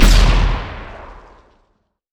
Grenade6Short.wav